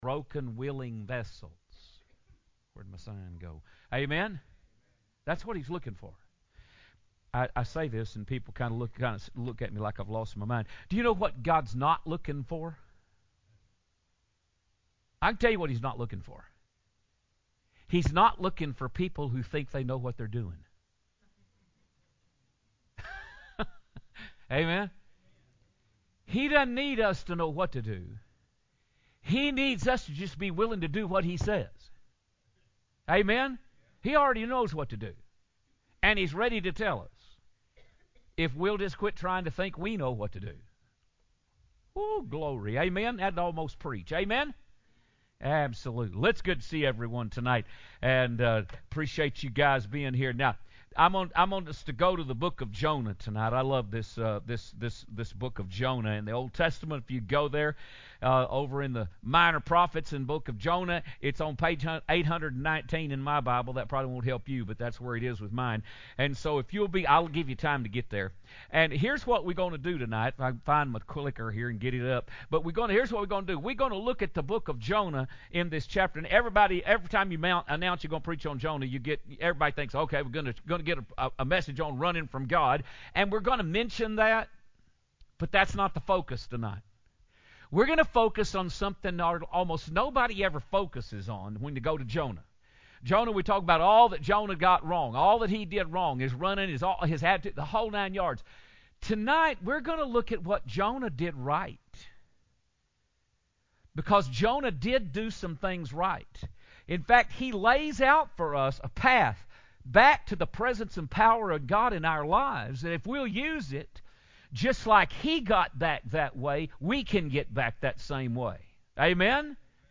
02/15/26 Revival Sunday Evening Service